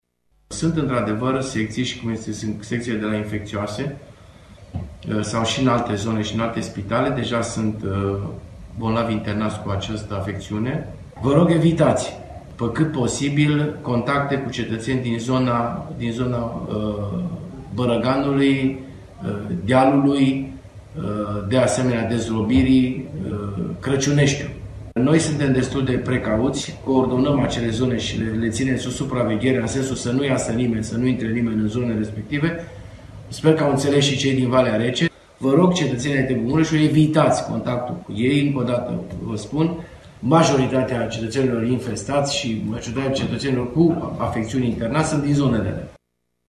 Într-un mesaj video postat pe pagina sa de facebook, edilul atenționează locuitorii municipiului că cele mai multe cazuri de îmbolnăvire cu COVID- 19 au fost înregistrate în aceste zone: